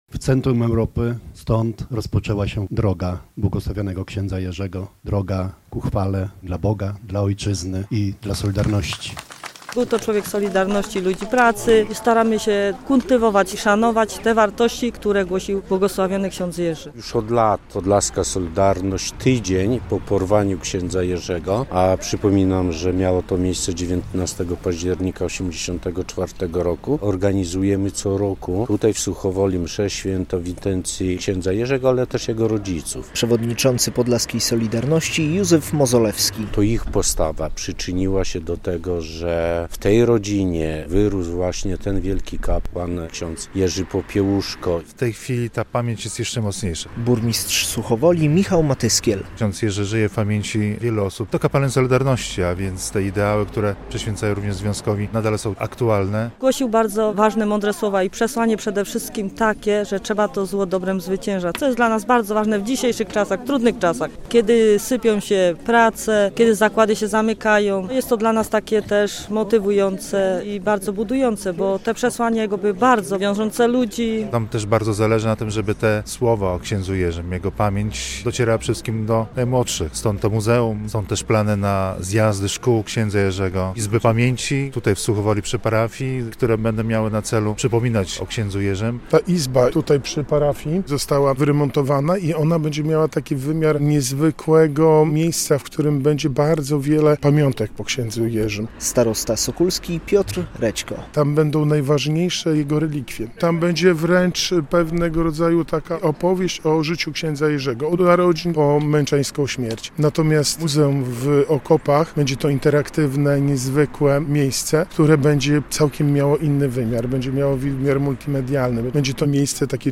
W Suchowoli przedstawiciele podlaskiej Solidarności, a także samorządowcy i delegacje z całego kraju wzięły udział w uroczystości z okazji rocznicy śmierci księdza Jerzego Popiełuszki.
Uroczystości w Suchowoli - relacja